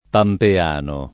pampeano [ pampe # no ]